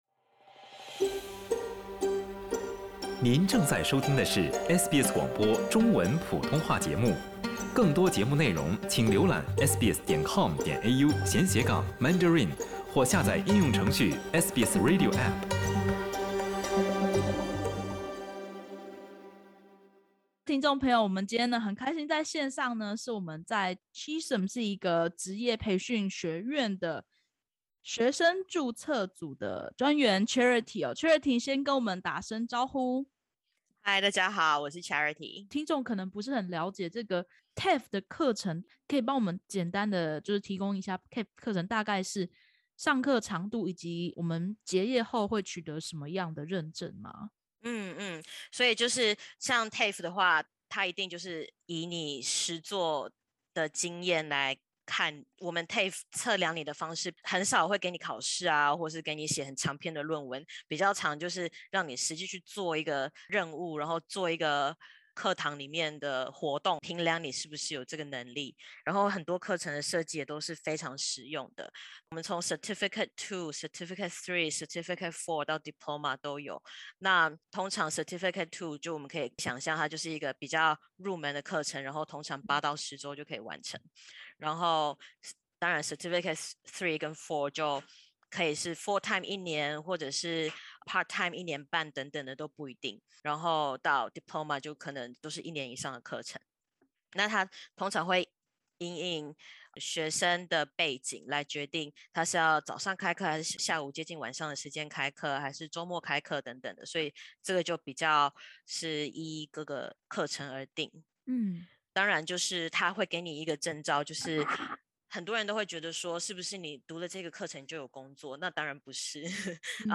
新年新希望，您了解如何善用政府福利，享用免费或低学费职场培训课，提升就业竞争力吗？ （点击首图收听采访音频）